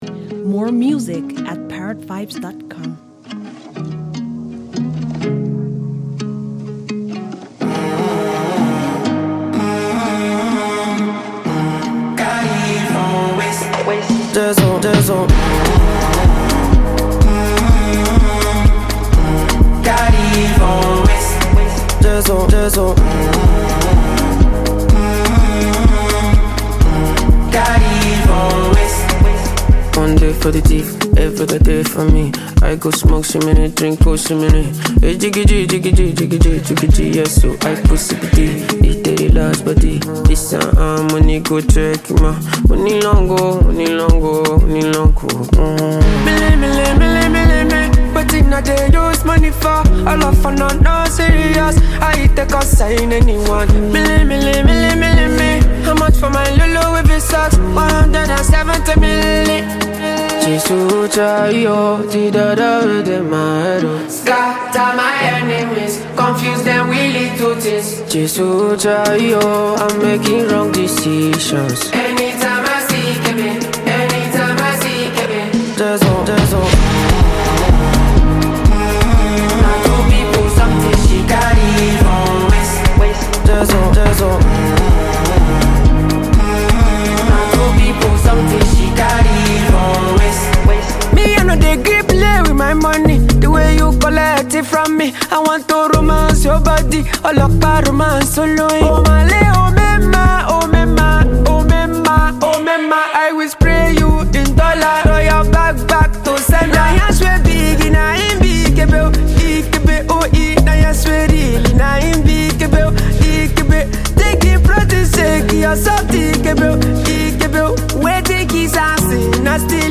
Prominent Nigerian singer
electrifying song